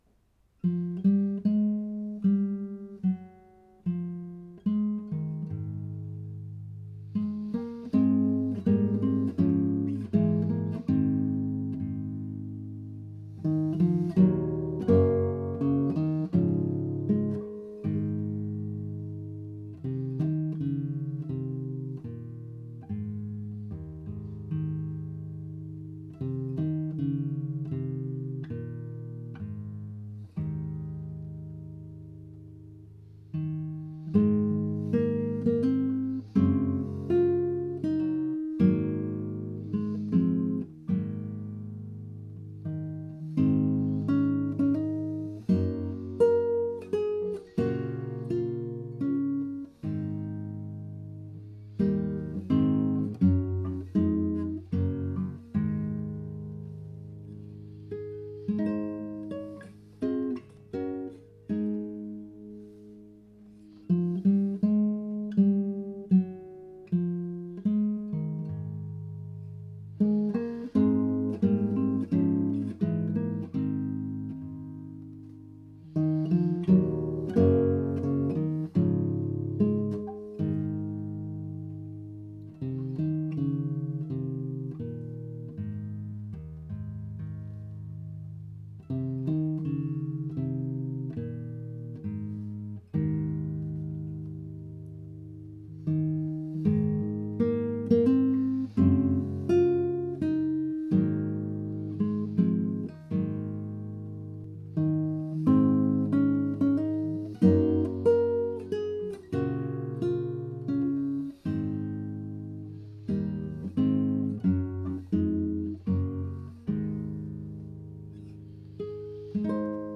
Homage to Sir Walter Scott (for guitar, 2020) SCORE |